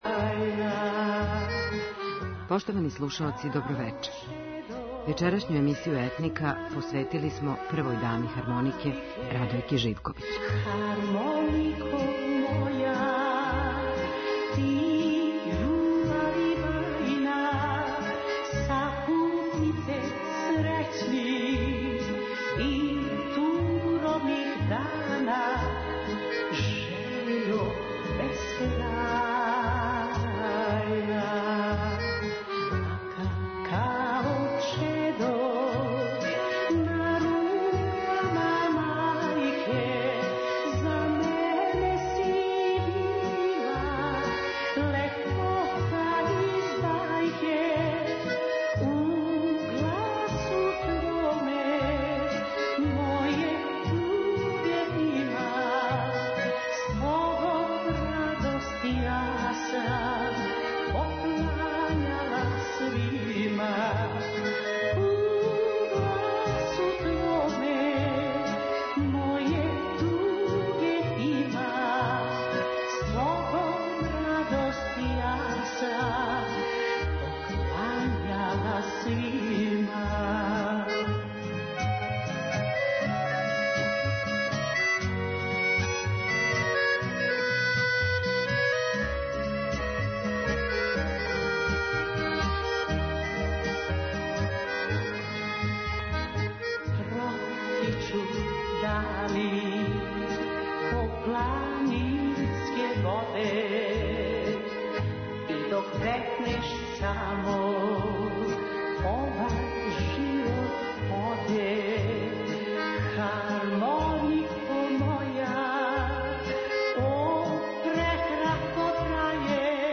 Инструменталисти на хармоници